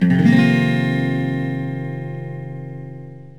F7sus4.mp3